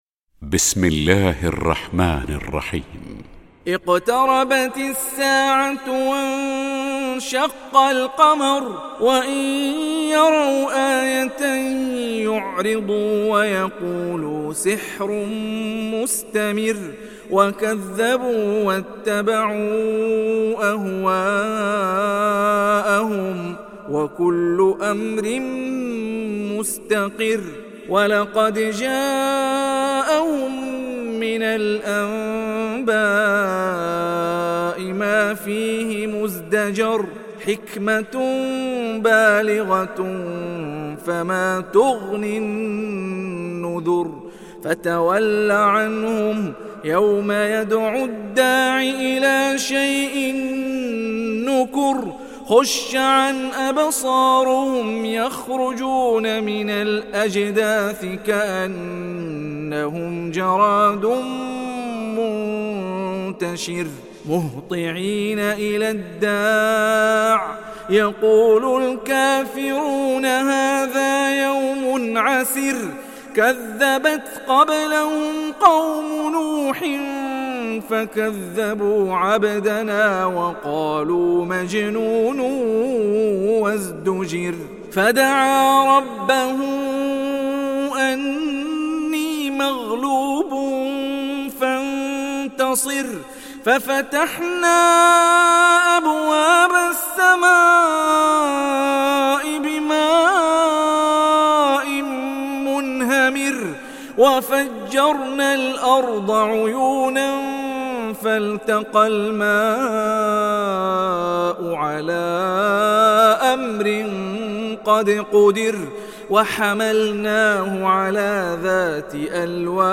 تحميل سورة القمر mp3 بصوت هاني الرفاعي برواية حفص عن عاصم, تحميل استماع القرآن الكريم على الجوال mp3 كاملا بروابط مباشرة وسريعة